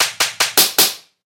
bitchslap.ogg